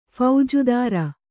♪ phaujudāra